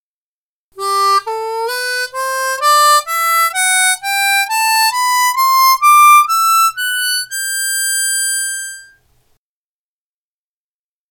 7thチューニング（C調）で、2番吸い音からスケールを吹いた時の音 （mp3形式：262KB）
7thtune.mp3